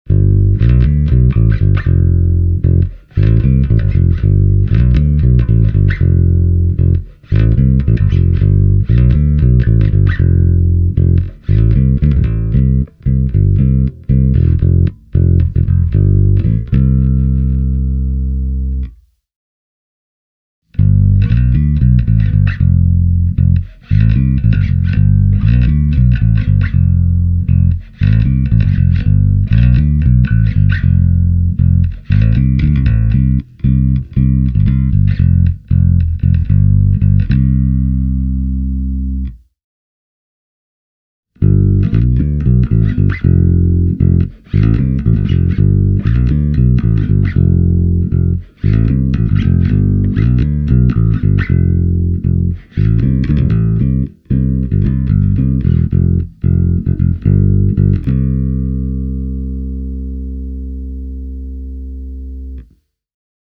Nämä Basslines-mikit eivät kavenna soittimen dynamiikkaa lainkaan, vaan basson luonnollinen ääni pysyy mallikkaasti ennallaan ja sen karakteeri säilyy. SJB-3-mallin lähtökohta on se perinteinen klassikkosoundi, jonka mikki ikäänkuin remasteroi tätä päivää varten – lopputulos on äänekkäämpi, isompi ja vielä entistäkin herkullisempi.
Tokai AJB-55BB – sormisoitto
fingerstyle.mp3